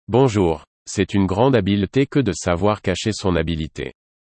Premium International Voices
Male French